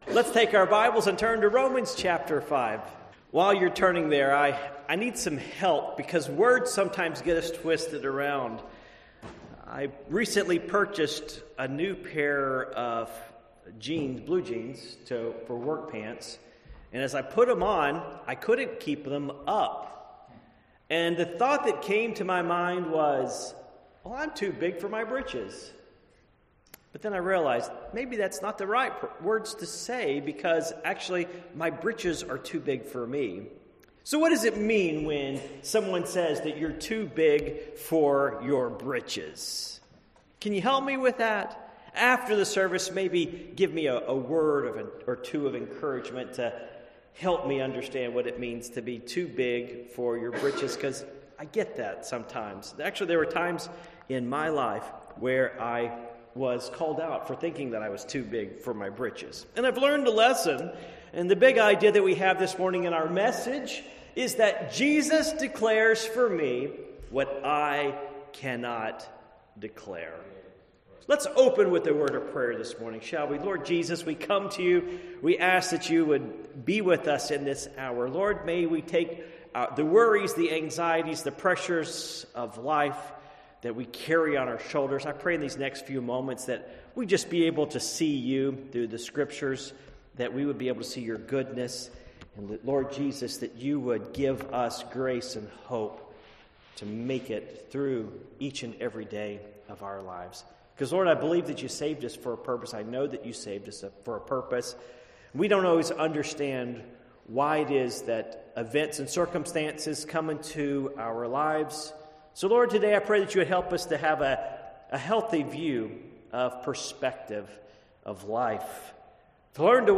Passage: Romans 5:1-5 Service Type: Morning Worship